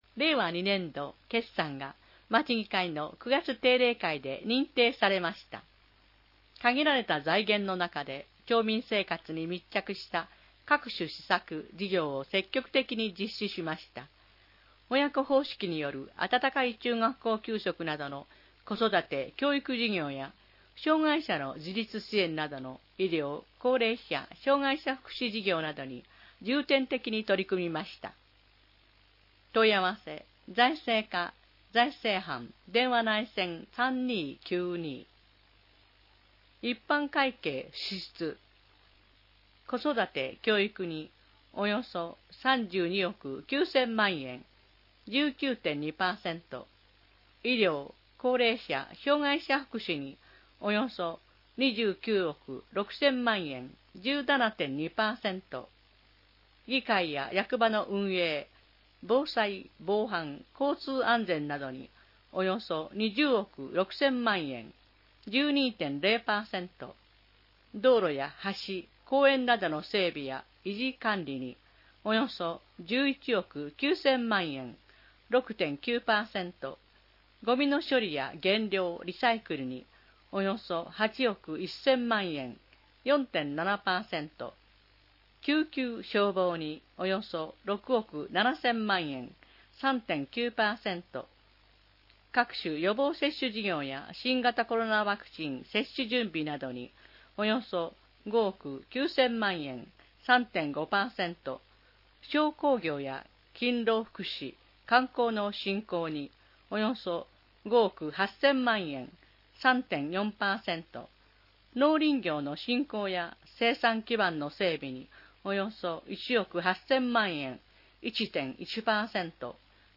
音声版「広報あいかわ」は、「愛川町録音ボランティアグループ かえでの会」の皆さんが、視覚障がい者の方々のために「広報あいかわ」を録音したものです。